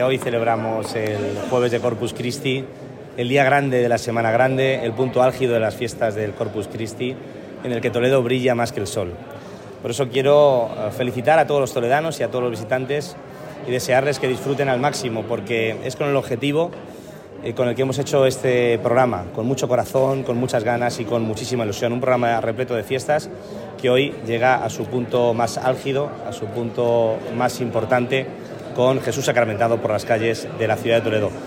El alcalde de Toledo, Carlos Velázquez ha felicitado hoy a todos los toledanos y a los visitantes que se acerquen a la ciudad un feliz Día Grande del Corpus Christi. Así lo ha manifestado el alcalde minutos antes de salir del Ayuntamiento bajo mazas junto a la corporación municipal para asistir a la Santa Misa en rito Hispano Mozárabe con motivo del Corpus Christi y a la posterior procesión por las calles de Toledo.